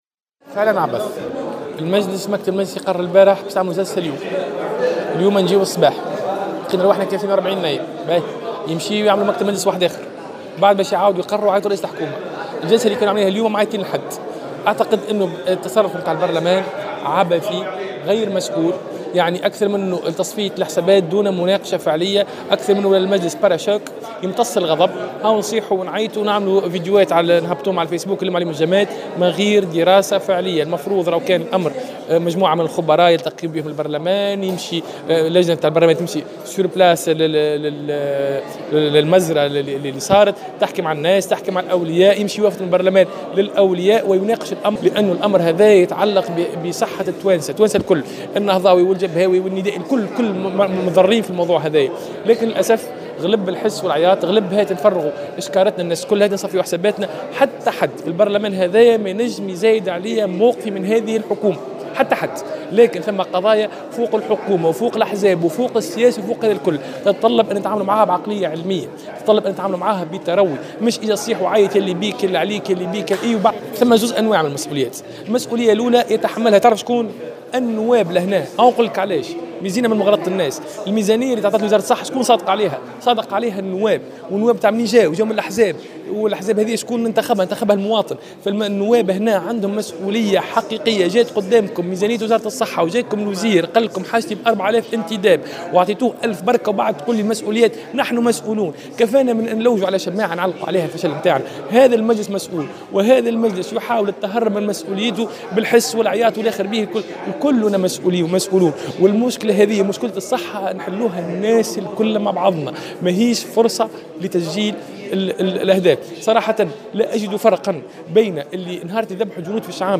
قال النائب ياسين العياري في تصريح لمراسل الجوهرة "اف ام" اليوم الثلاثاء إن قرار المجلس استدعاء رئيس الحكومة لمساءلته بخصوص حادثة وفاة الرضع هو تصرف عبثي وغير مسؤول هدفه الوحيد تصفية الحسابات السياسية دون مناقشة فعلية لحقيقة ما يحدث .